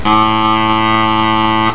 Here are some pipe sound samples randomly collected from volunteering MMD subscribers. They are generally resampled into 11.025 kHz. In several cases it was also appropriate to edit, gain adjust, and/or 20 Hz highpass filter to remove a DC component from the signal.
Reed pipes
Typical features: Loud and wideband source spectrum. Spectrum envelope is relatively independently shaped by the resonator characteistics.
Experimental, aluminium reed 21*23*0.2 mm, bamboo shallot.
Mismatch between resonance and reed fundamental gives little coupling between them and pitch is highly dependent on pressure.
dgstk: cylindrical resonator, bamboo, L 142, D 9 mm. Resonance frequencies about 1100*(1+2n) Hz with low Q. Spectrum probably represents reed alone.